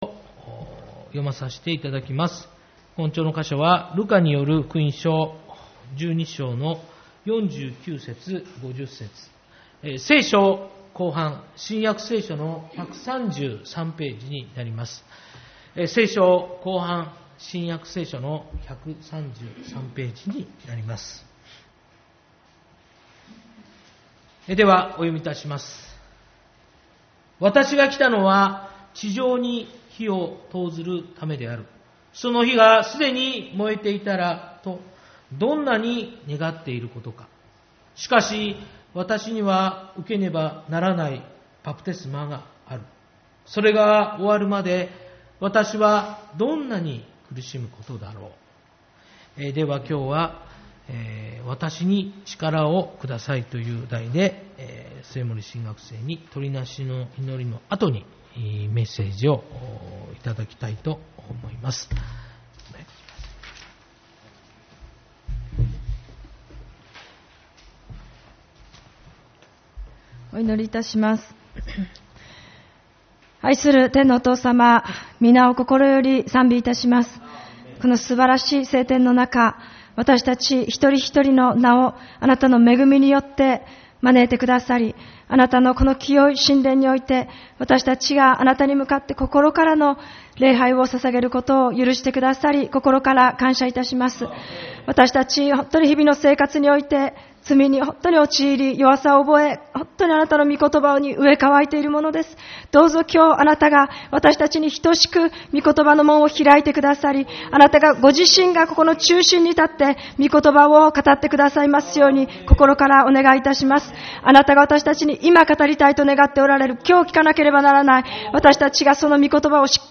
8月18日主日礼拝 「わたしに力をください」